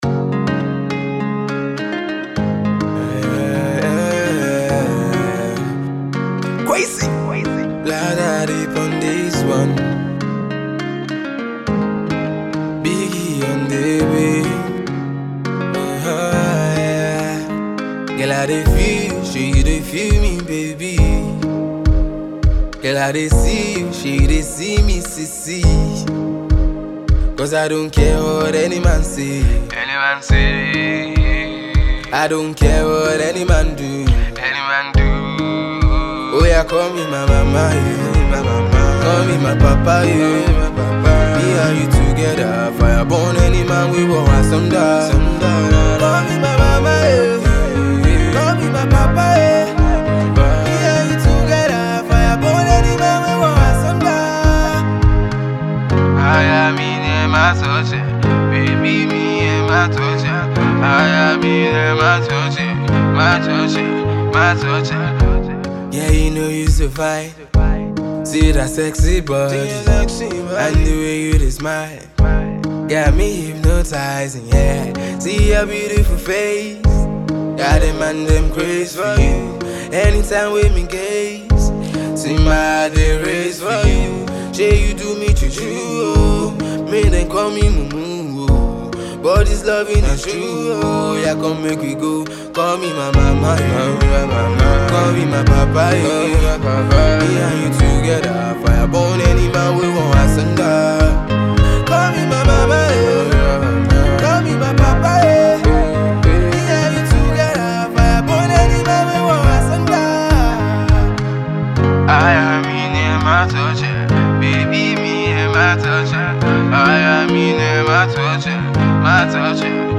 pop act